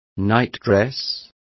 Complete with pronunciation of the translation of nightdress.